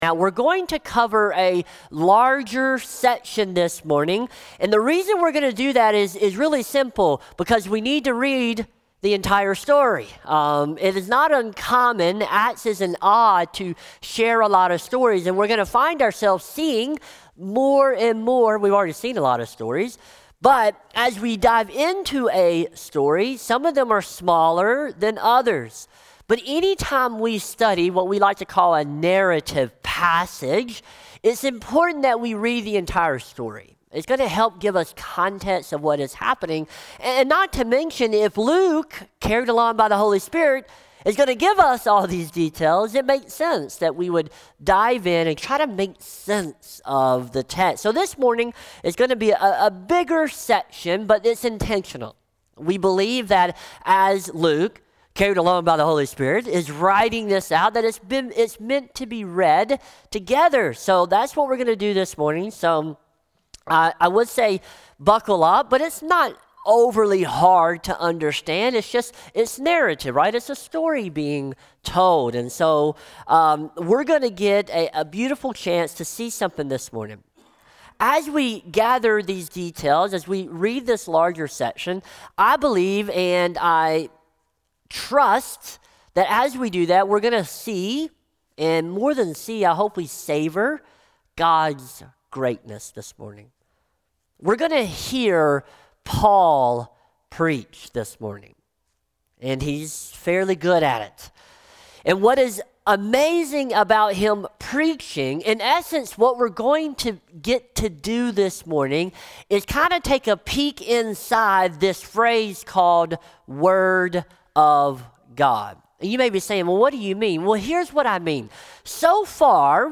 SERMON | Acts 13:13-52 | Jews Who Reject, Gentiles Who Rejoice | Light in the Desert Church